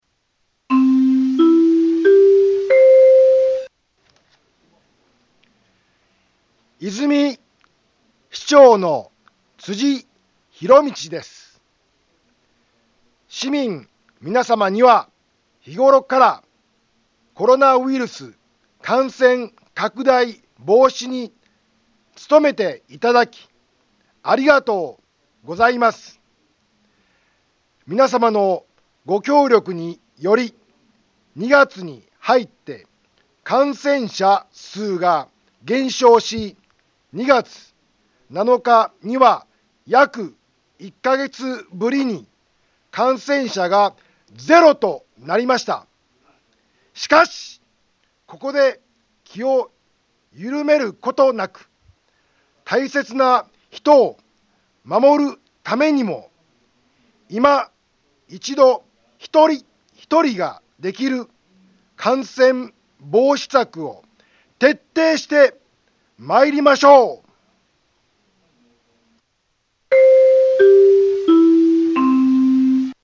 Back Home 災害情報 音声放送 再生 災害情報 カテゴリ：通常放送 住所：大阪府和泉市府中町２丁目７−５ インフォメーション：和泉市長の、辻 ひろみちです。